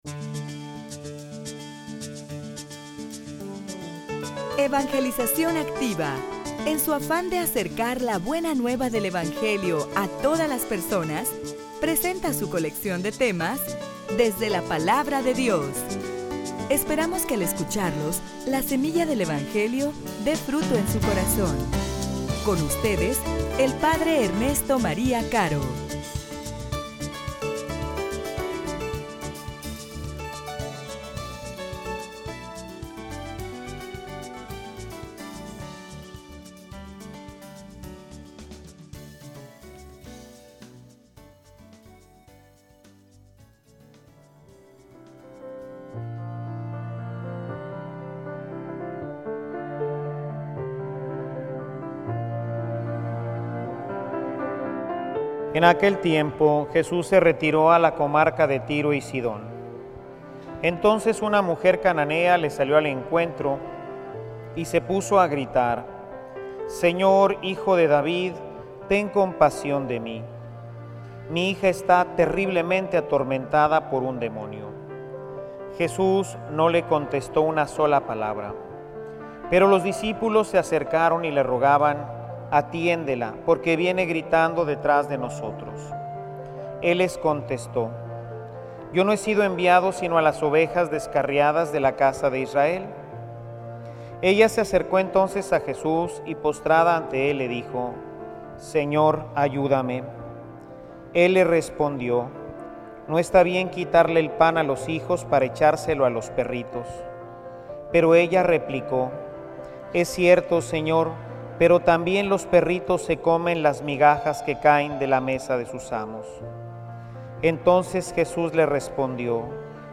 homilia_La_fe_de_la_Cananea.mp3